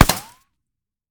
3098b9f051 Divergent / mods / JSRS Sound Mod / gamedata / sounds / material / bullet / collide / concrete01gr.ogg 32 KiB (Stored with Git LFS) Raw History
concrete01gr.ogg